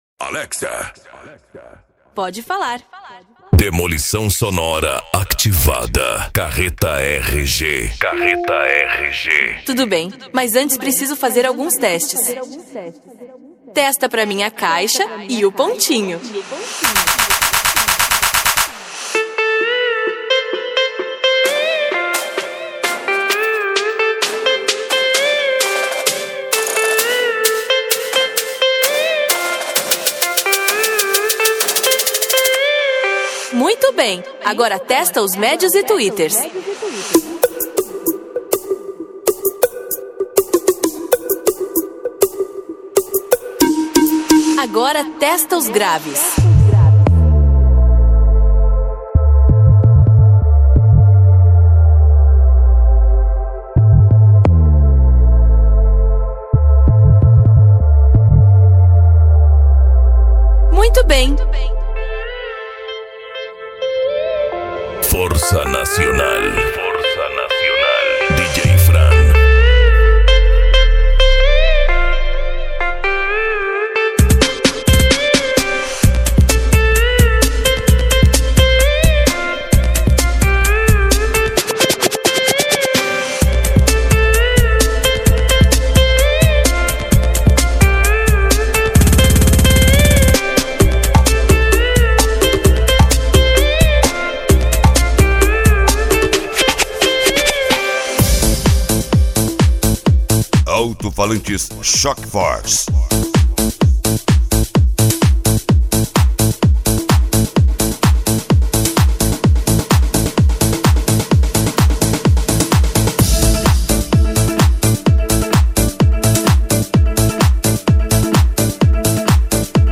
Bass
Remix